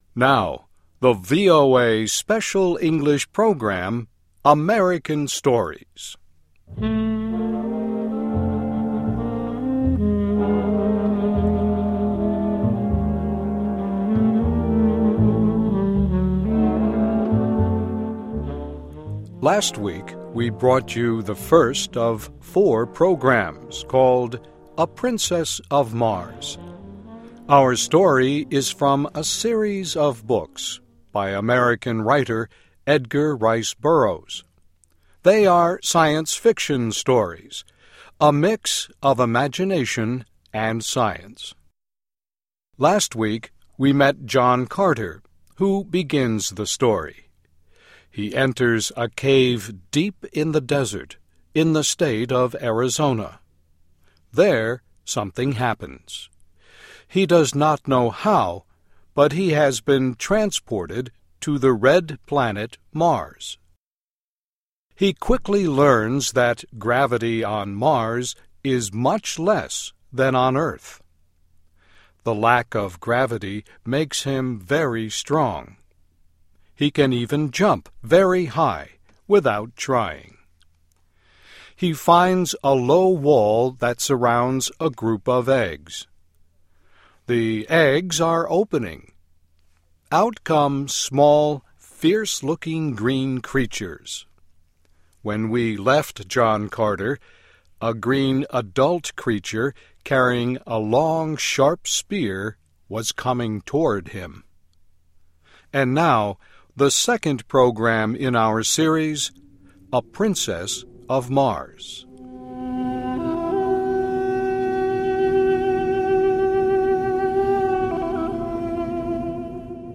Last week we brought you the first of four programs called ��A Princess of Mars.�� Our story is from a series of books by American writer Edgar Rice Burroughs. They are science fiction stories, a mix of imagination and science.